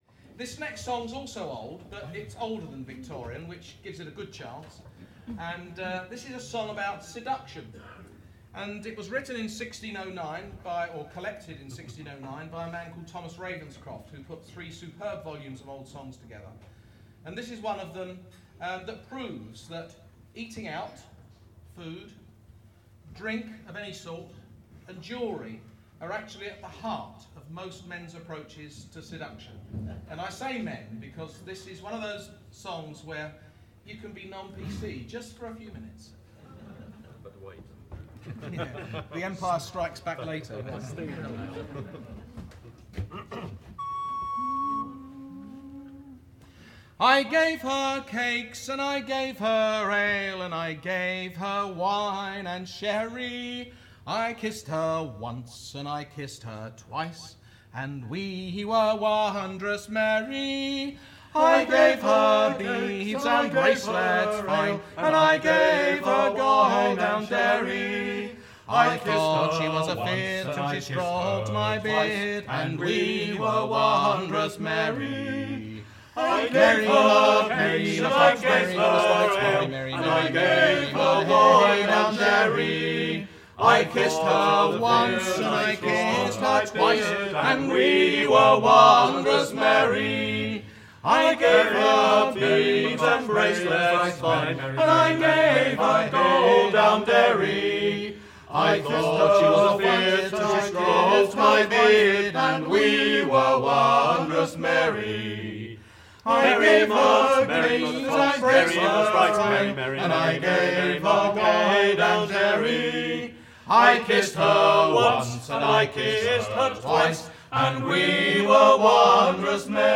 Naked Voices ~ 12 talented singers in Brighton, Sussex ~ Music
These tracks, recorded live at the Brighton Komedia, are all in our current repertoire.